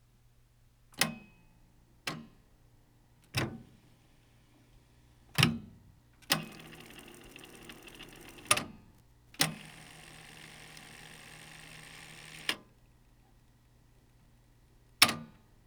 動作音
【メカニズム動作音】カセット装着・弛み取り機能→再生→停止→早送り→停止→巻戻し→停止